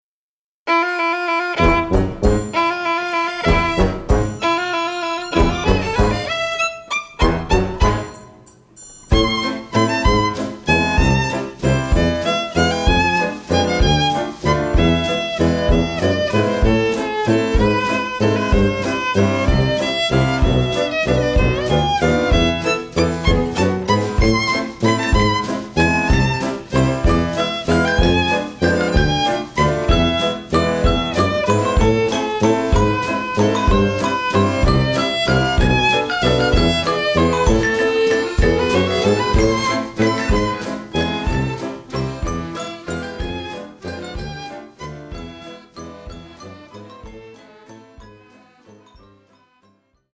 撮りたての音源を瞬時に冷凍パックすべくレコーディング日数２日のみ！、基本いっせいのせの一発撮り！、
時代錯誤のオープンリール！、ライヴ感重視で仕上げました。
※ ここで聞ける音源はダイジェスト版に過ぎず、短くまとめたリスニング用のものですので、実際の音源とは異なります。